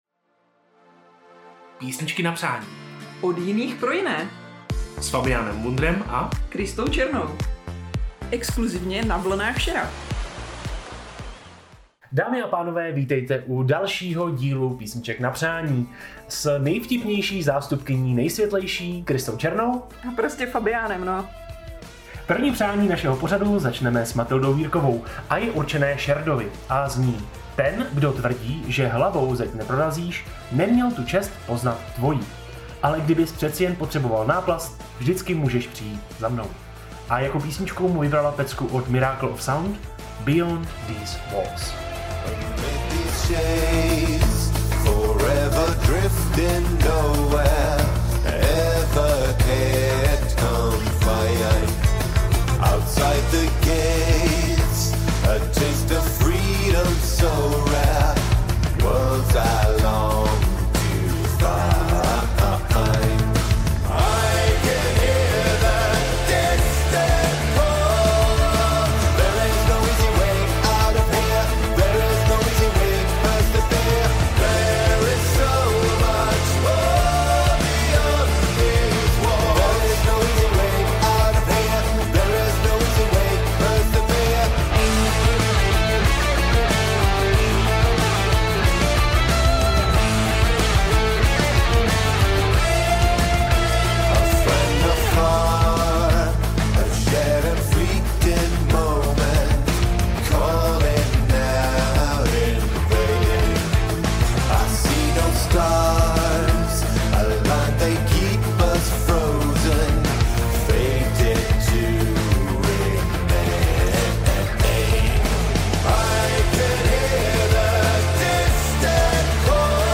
Jedenáctka pokračuje v tradici: trochu hudby, trochu tajných vzkazů a trochu toho bacátka, ale reálně hlavně vaše vzkazy a přání od těch, co mají den těžší než by chtěli, i od těch, kteří jen potřebují svůj soundtrack.
Krátké, svižné, osobní — přesně tak, jak to máme na NVŠ rádi.